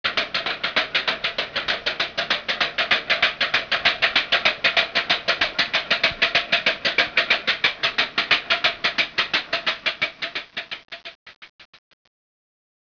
A TRACCUL svolge una specifica funzione sonora rituale ( strumento a percussione formato da tavolette mobili battenti su un corpo centrale con manico) - nei giorni del Triduo pasquale, quando la Chiesa vieta l'uso delle campane. Spetta, infatti, alle traccule annunciare gli itinerari processionali e i riti penitenziali.
QUESTA FORMULA VIENE RECITATA NEL MOMENTO IN CUI LE CAMPANE RICOMINCIANO ENERGICAMENTE A RISUONARE FORA FORA CMMCION CA JE TRASCIUT NOSTRO SIGNOR A TRACCUL”:- era formata da una tavola di circa cm 30x20 con manico a due aste di legno squadrate, oscillanti in entrambi i lati secondo il movimento della mano, e produceva un suono caratteristico ................